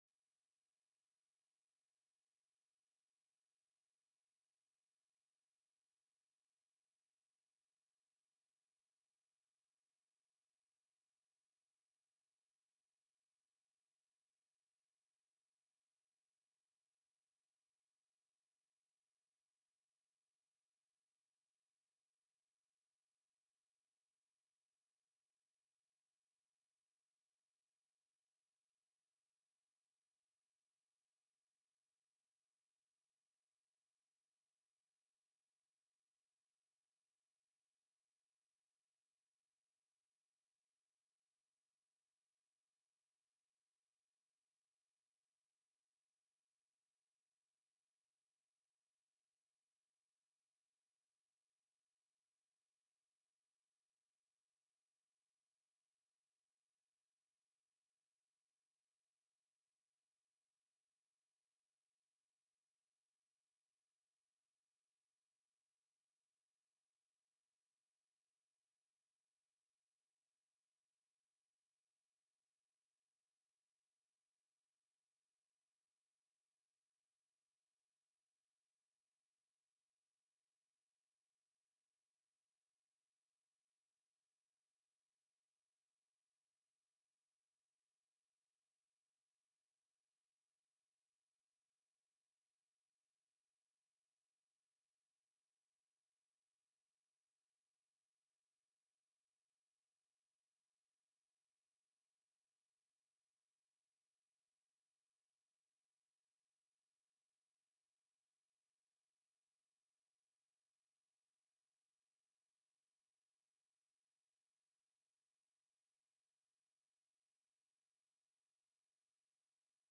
03/18/2026 01:30 PM Senate LABOR & COMMERCE
The audio recordings are captured by our records offices as the official record of the meeting and will have more accurate timestamps.